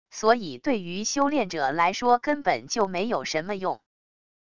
所以对于修炼者来说根本就没有什么用wav音频生成系统WAV Audio Player